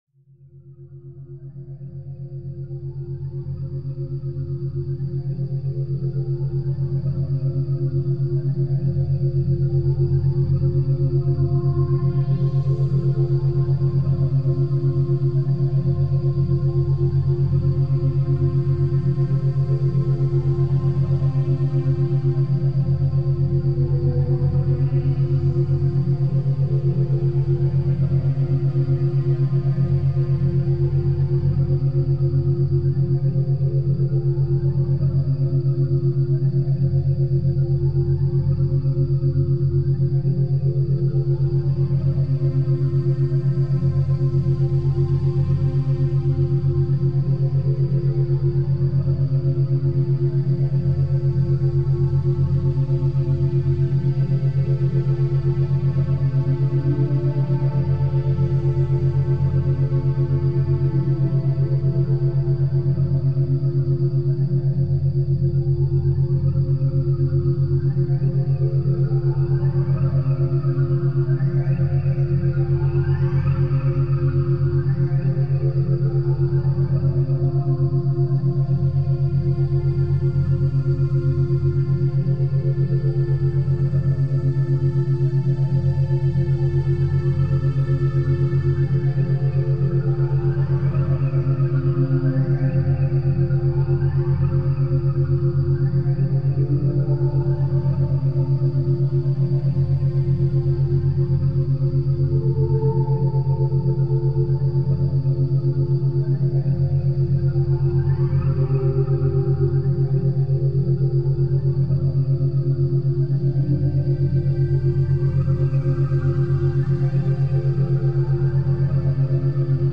Full Body Regeneration with 7.83Hz & 3.5Hz Frequencies